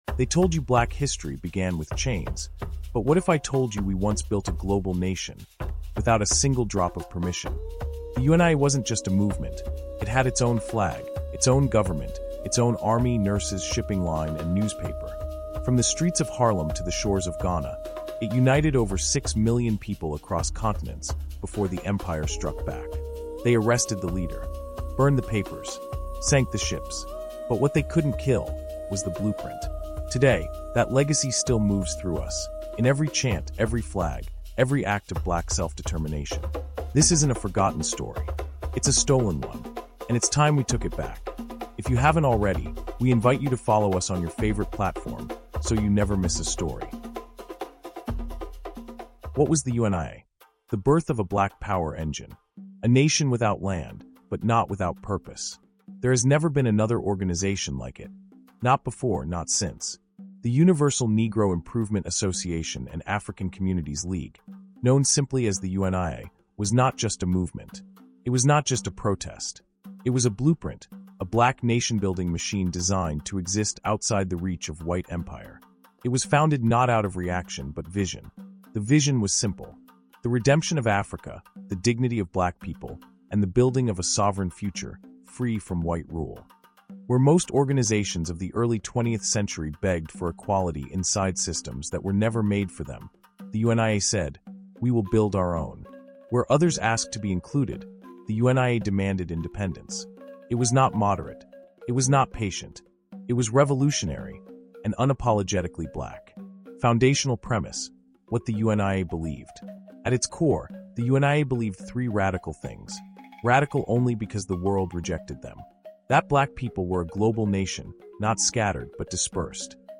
This 10-chapter cinematic audiobook dives deep into the untold story of the UNIA — the Universal Negro Improvement Association.